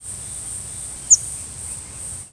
pure-toned: Refers to a call that lacks audible modulations, thus with a clear, simple sound (e.g.,
Yellow-throated Warbler).